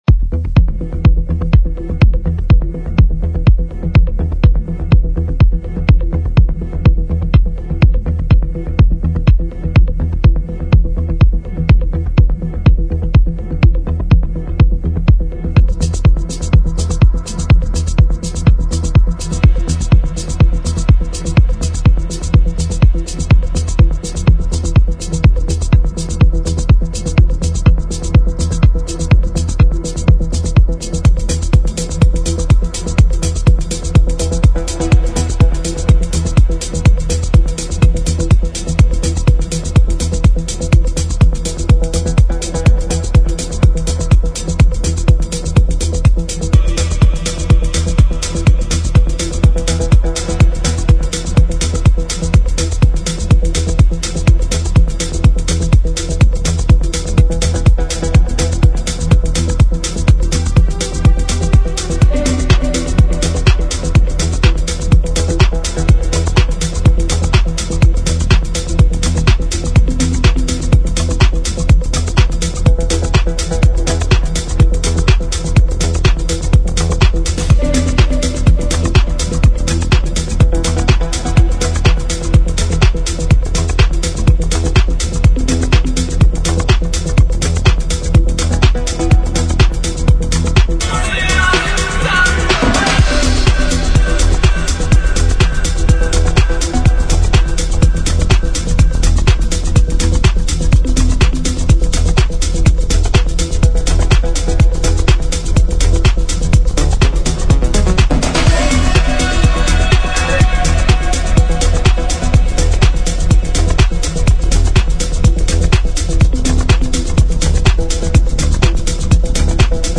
reconstruidas en tiempo house.